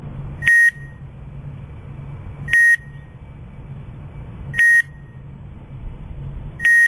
Listen to Tone Options
low-volume-beep.mp3